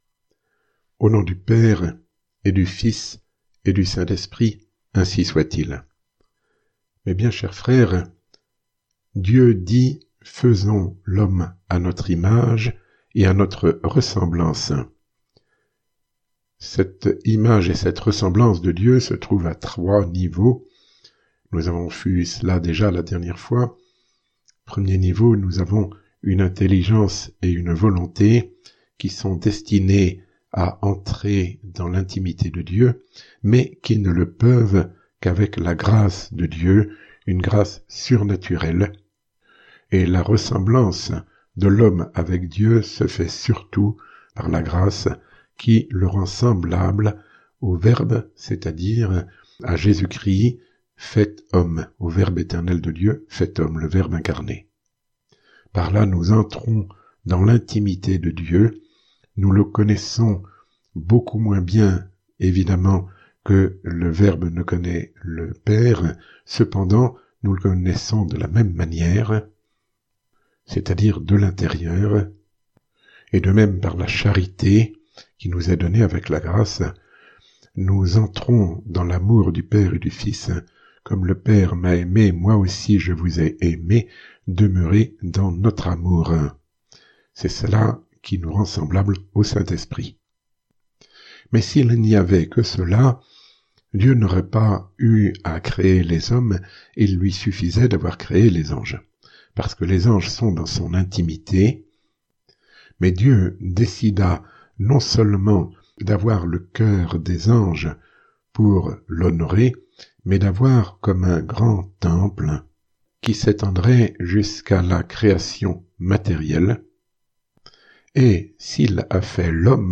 Sermon ~ La Genèse 06.